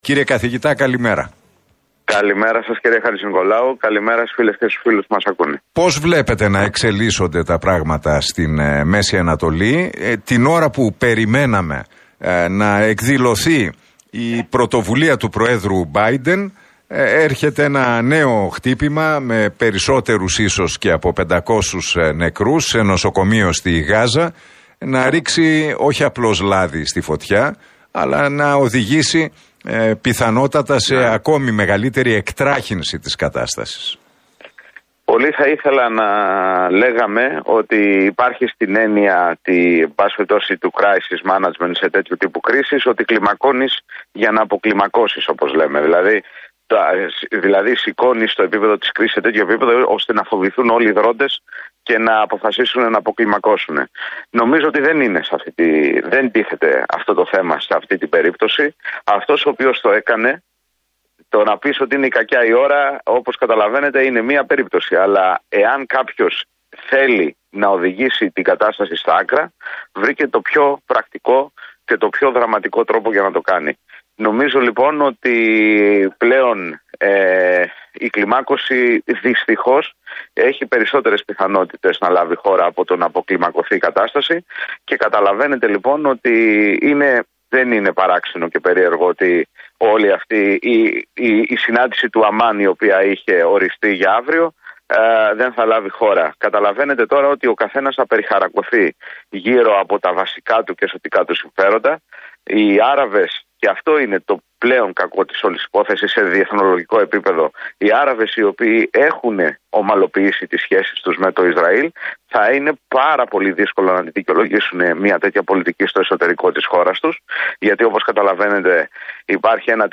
σε συνέντευξή του στον Realfm 97,8 και στην εκπομπή του Νίκου Χατζηνικολάου δήλωσε «αν κάποιος θέλει να οδηγήσει την κατάσταση στα άκρα